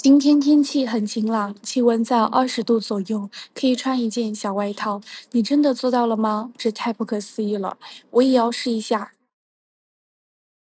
专业正式旁白配音
使用专为企业通讯、纪录片和教育内容设计的先进 AI 声音，权威而精准地传达您的信息。
文本转语音
权威语调
发音精准